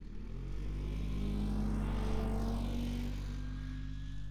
Internal Combustion Subjective Noise Event Audio File (WAV)